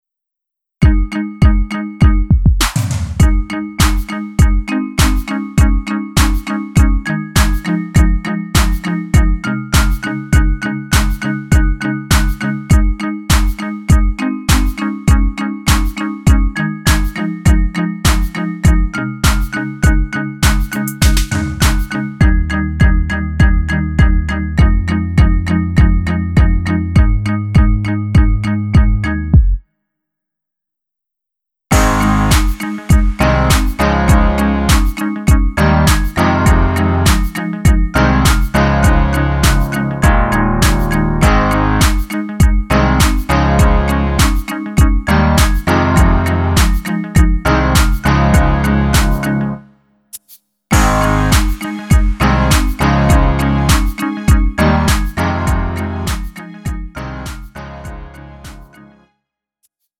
음정 -1키 3:07
장르 가요 구분